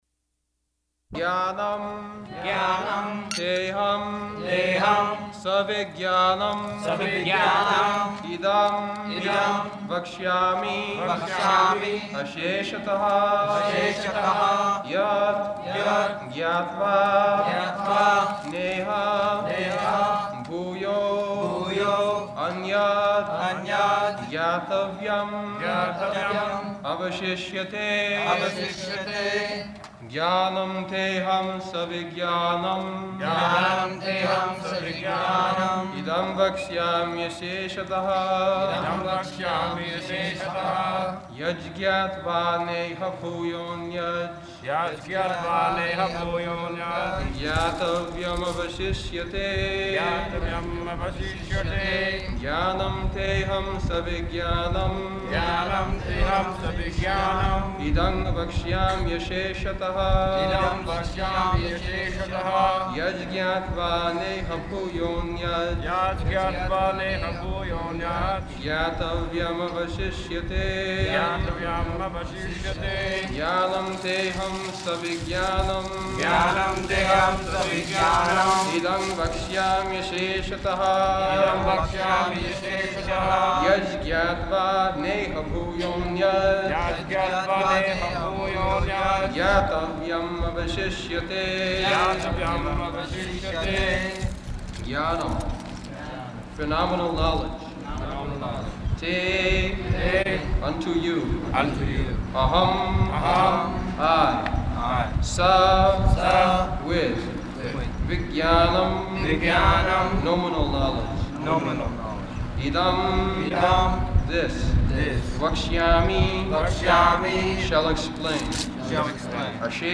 Location: Hyderabad
[leads chanting of verse] [devotees repeat]